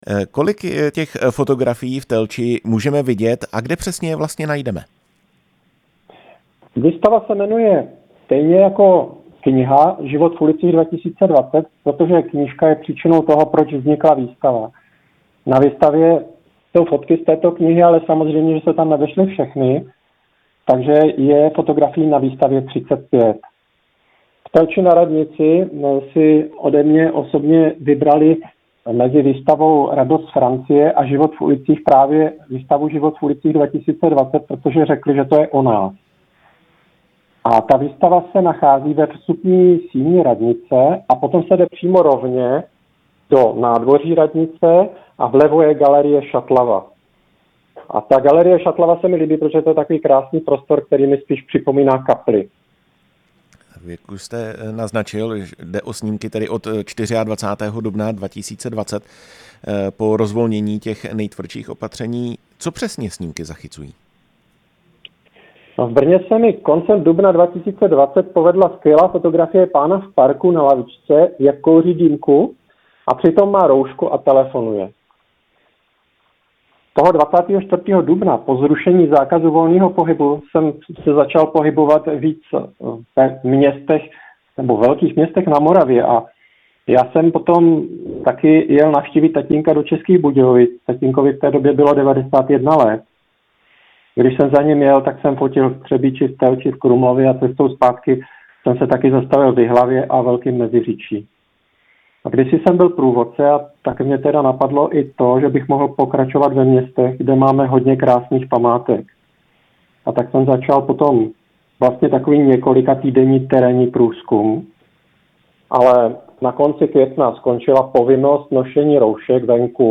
Rozhovor s fotografem